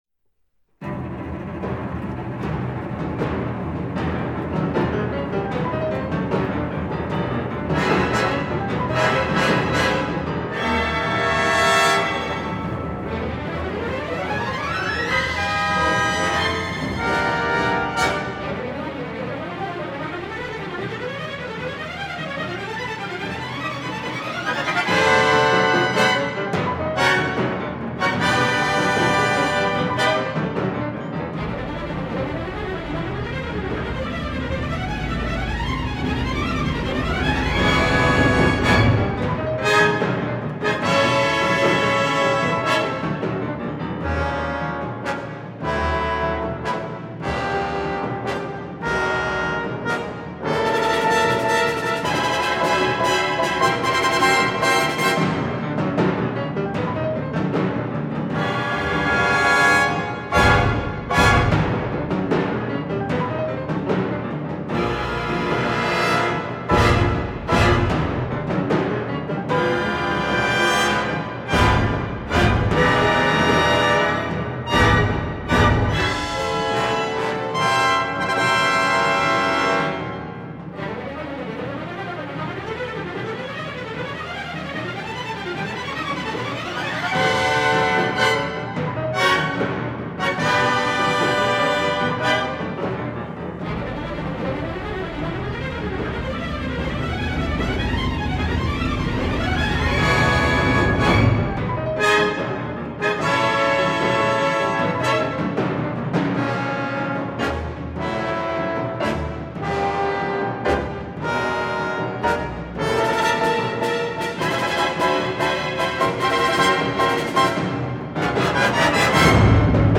Rolf Liebermann (1910-1999): Furioso for Orchestra (Allegro vivace, furioso-Andante-Allegro vivace, tempo I).